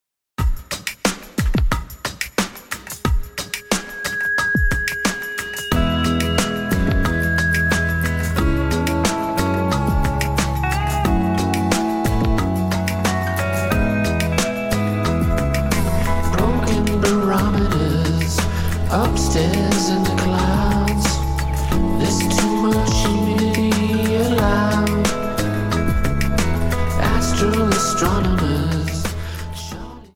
Alternative,Blues,New Age